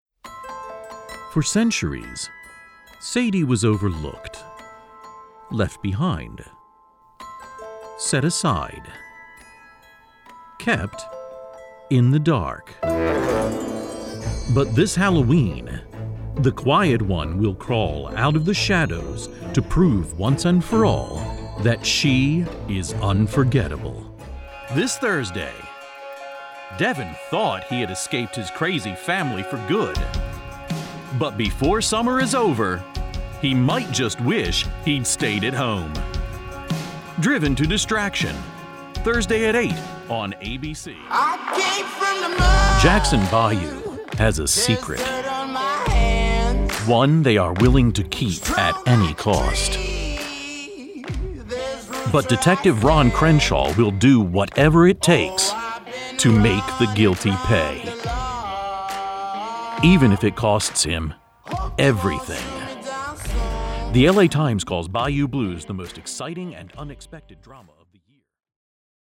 Male
Adult (30-50), Older Sound (50+)
Television Promo
Words that describe my voice are dynamic, articulate, versatile.